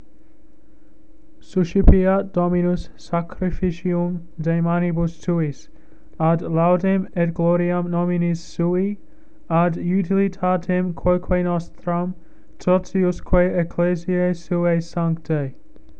Soo-ship-ee-aht dom-ee-noos    sah-kree-fee-chee